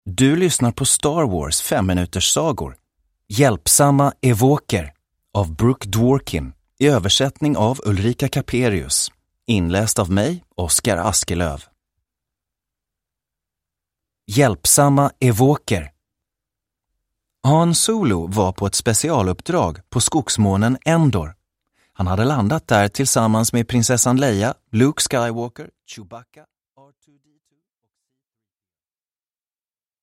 Hjälpsamma ewoker. Nionde berättelsen ur Star Wars 5-minuterssagor – Ljudbok – Laddas ner